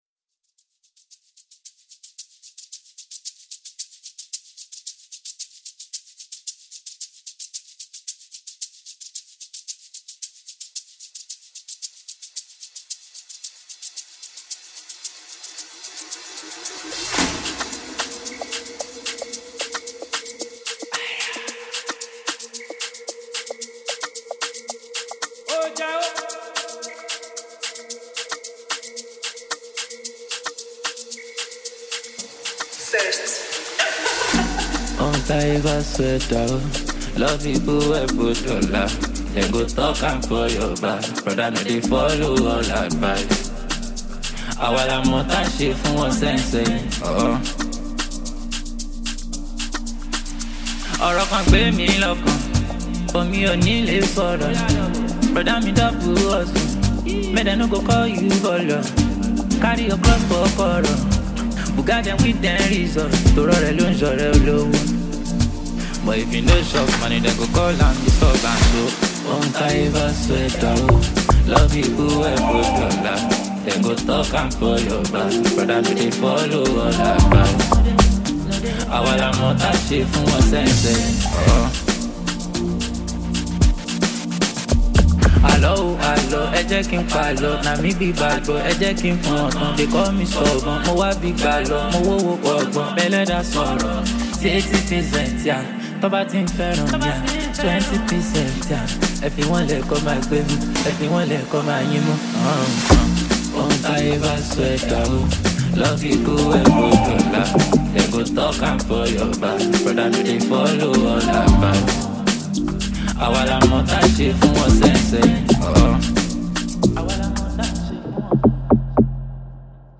gbedu song